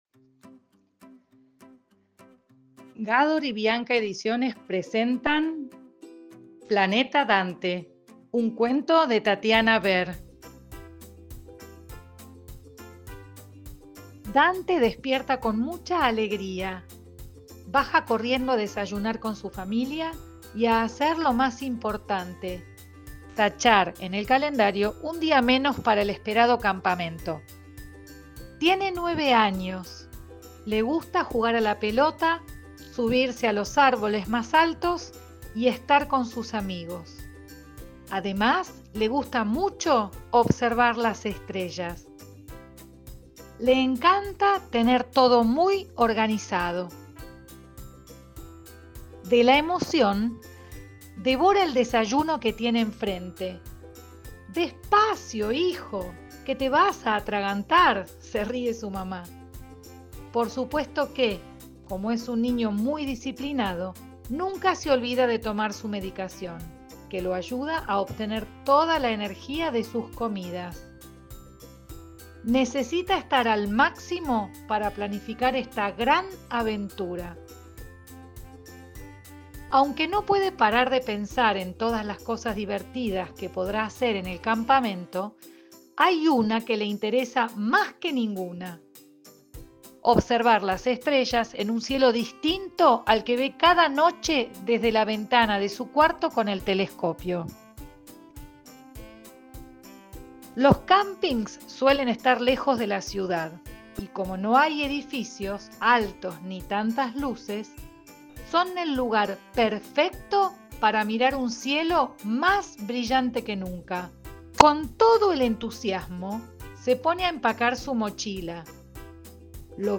Audiolibro “Planeta Dante”
Planeta-Dante-Audiocuento.mp3